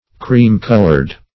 Cream-colored \Cream"-col`ored\ (-k?l`?rd), a.